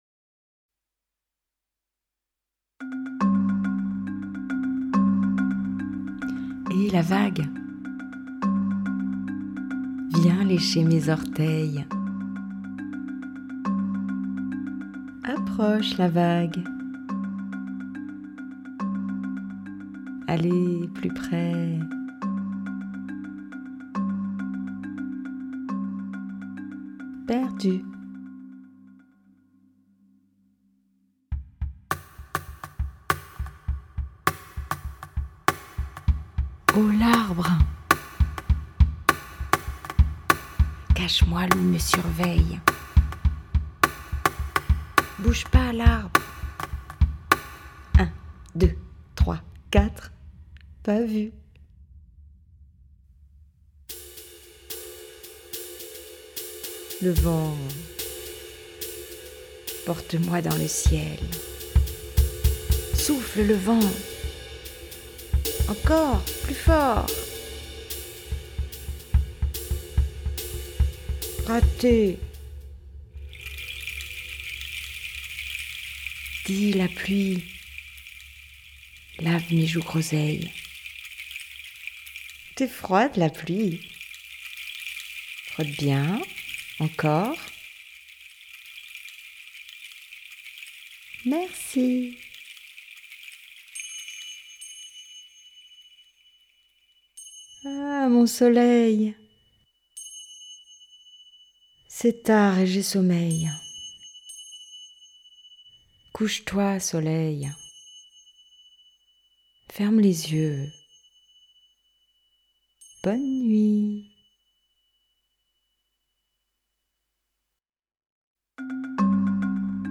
création musicale
lecture en français
lecture en arabe
001-Un-deux-trois-francais-et-arabe.mp3